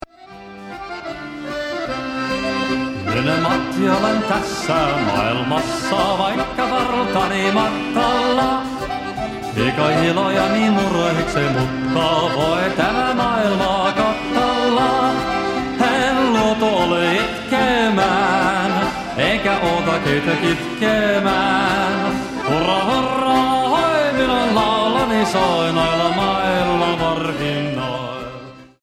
accordion
drums and vocals